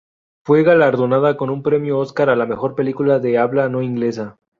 Pronúnciase como (IPA) /ˈoskaɾ/